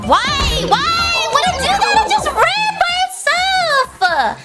Worms speechbanks
Stupid.wav